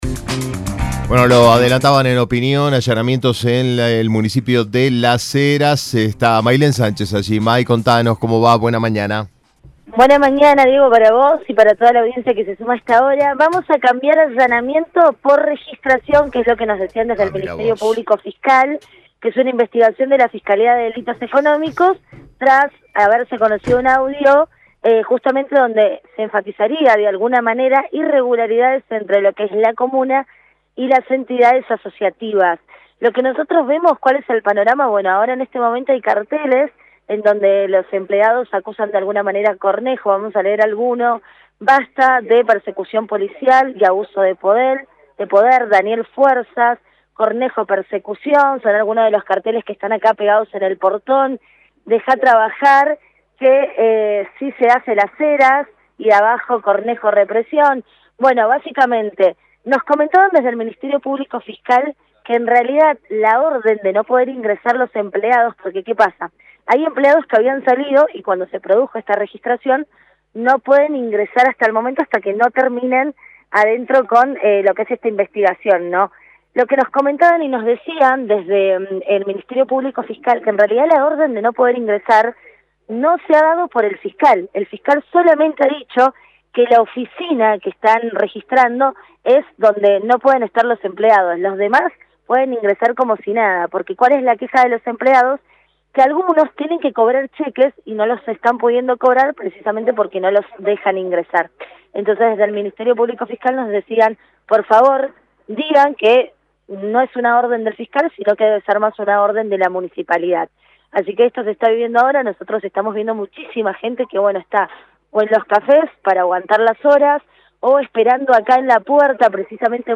LVDiez - Radio de Cuyo - Móvil de LVDiez- allanamiento de registración en la Municipalidad de Las Heras